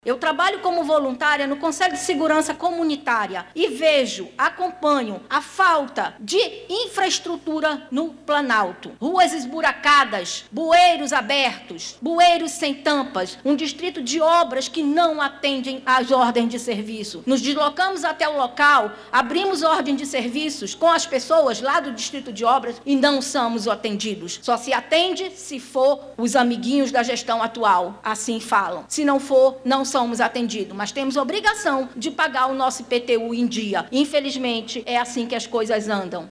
A Câmara Municipal de Manaus – CMM realizou nesta quarta-feira 17/04, uma Tribuna Popular para receber as demandas dos moradores da zona Centro-Oeste da capital amazonense.